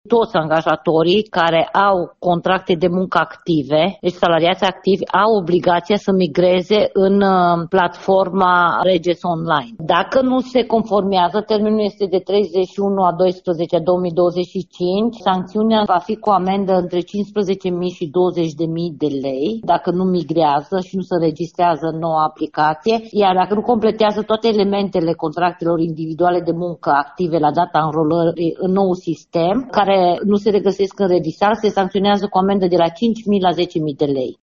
Ileana Mogoșanu, inspector șef al Inspectoratului Teritorial de Muncă Timiș: